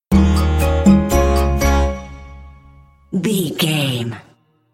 Aeolian/Minor
piano
percussion
flute
silly
goofy
comical
cheerful
perky
Light hearted
quirky